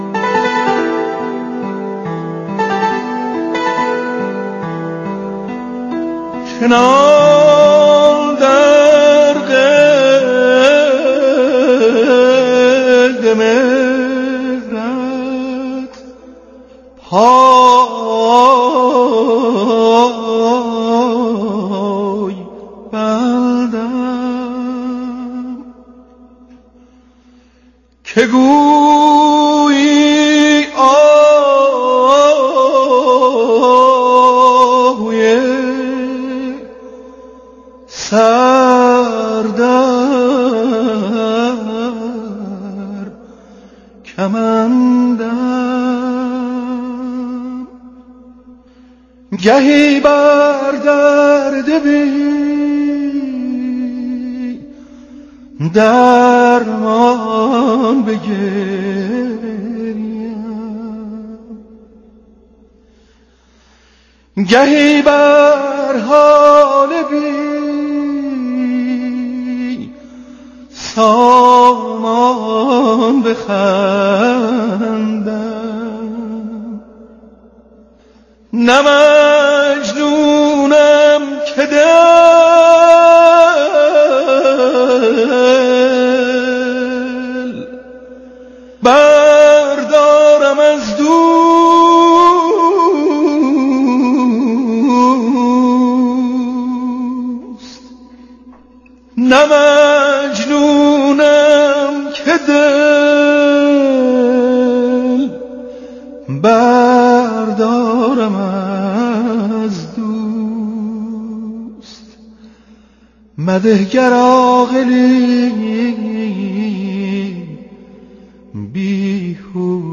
اما، درخصوص درک بیشتر موضوع و ارائه‌ی مصداقی در مورد موسیقی آوازی، سه روایت از گوشه‌ی دیلمان (باصدای محمود کریمی، هوشمند عقیلی و بانو پریسا) را با هم می‌شنویم؛ جالب این‌که، محمود کریمی معلم آوازیِ هوشمند عقیلی و پریسا بوده و روایت دیلمانِ عقیلی و پریسا متأثر از ردیف آوازیِ به‌یادگارمانده از استاد است.